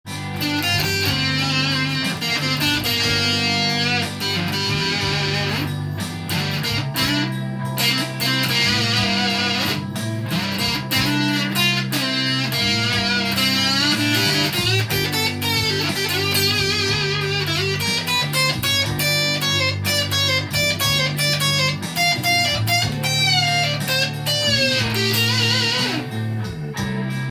ギターソロも弾いてみました
LPのレコードから聞こえそうな古い音がします。
ビンテージサウンドというものなりそうです。
rotosound.solo_.m4a